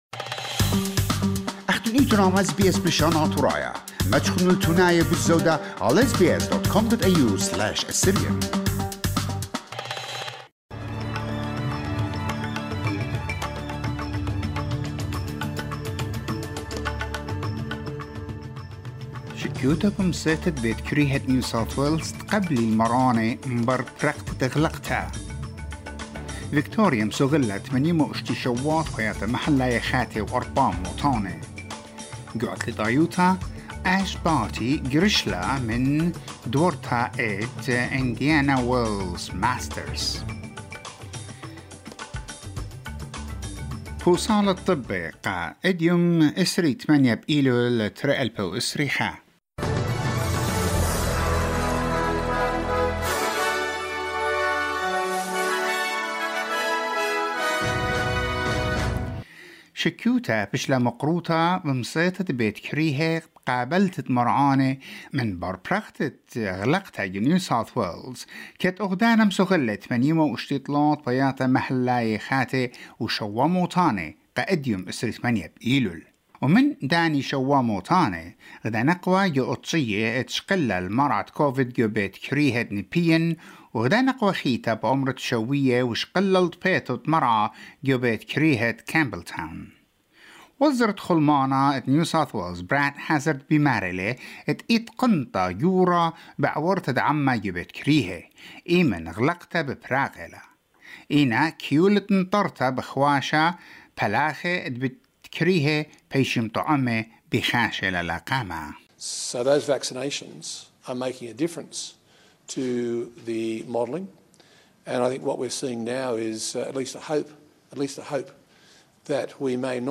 SBS NEWS BULLETIN 28 SEPTEMBER 2021